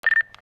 TM88 - FX (6).wav